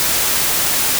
Let's look at a signal with a lot of noise:
less10mynoise.wav